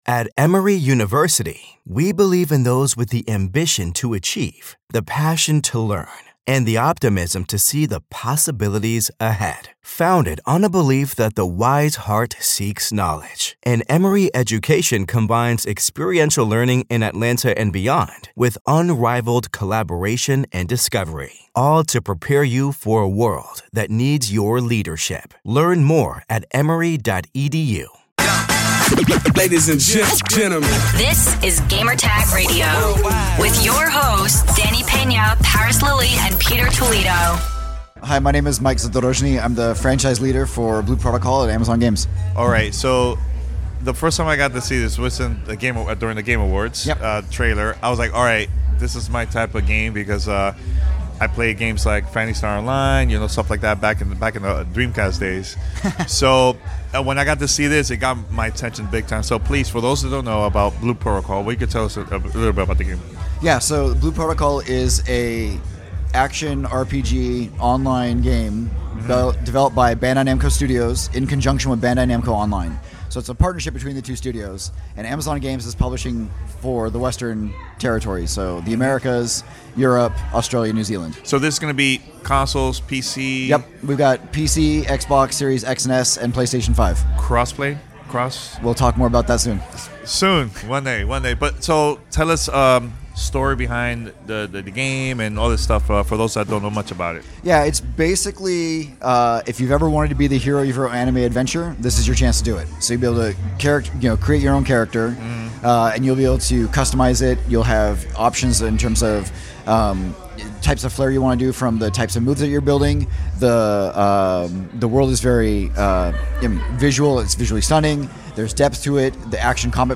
Blue Protocol Interview
Blue Protocol Interview Bonus episode.